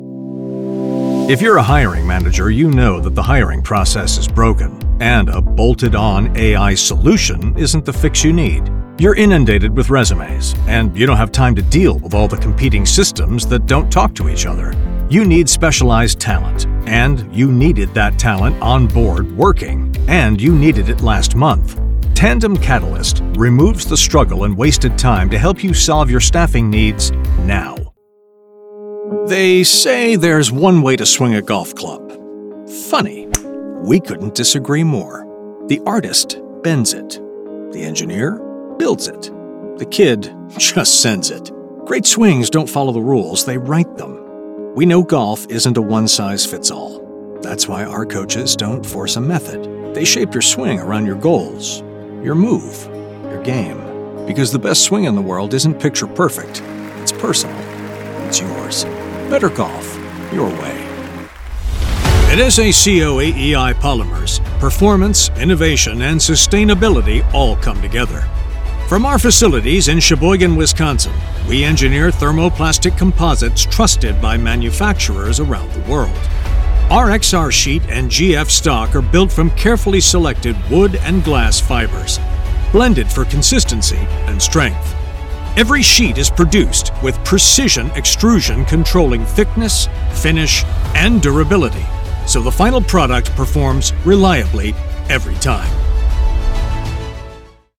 Vídeos corporativos
His voice has been described as Articulately Conversational.
Neumann TLM-103
Custom designed broadcast quality Whisper Room
Mediana edad